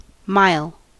Ääntäminen
US : IPA : [maɪl] UK : IPA : /maɪ̯l/ US : IPA : /maɪ̯l/